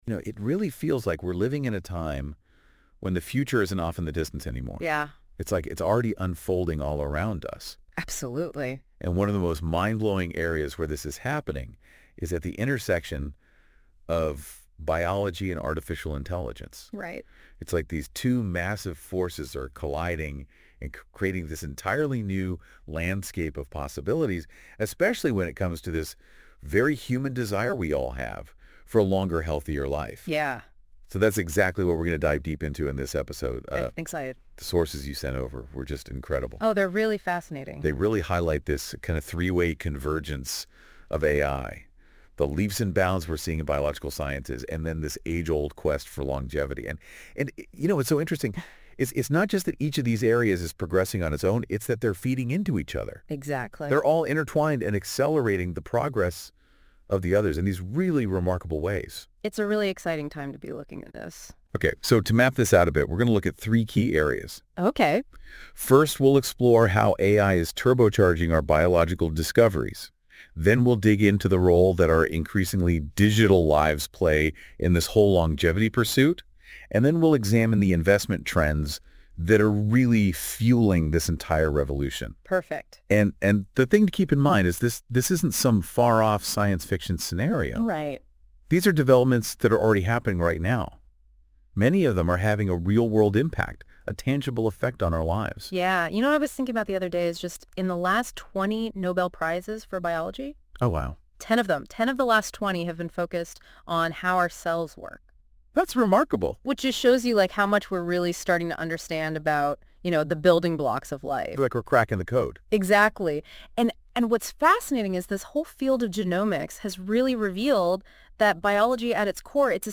I gave a talk in Stockholm last year, that I asked these robots to discuss.